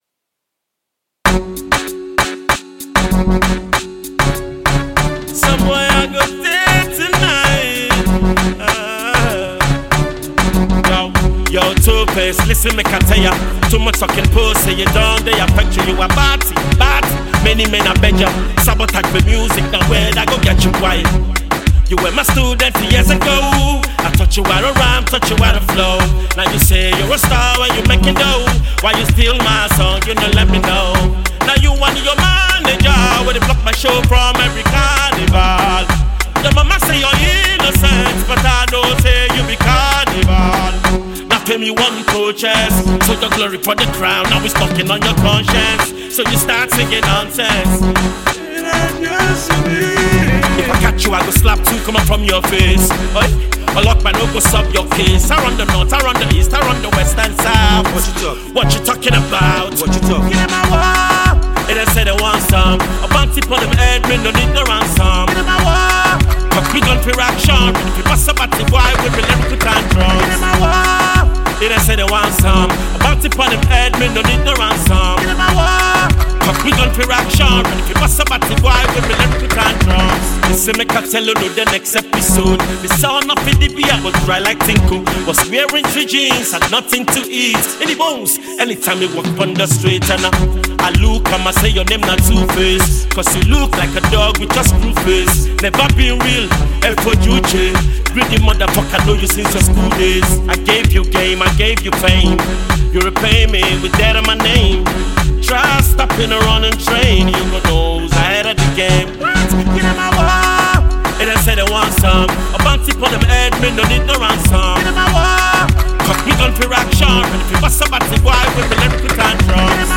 has just released a diss song for his former co-band mate